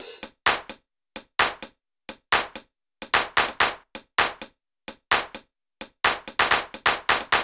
BAL Beat - Mix 12.wav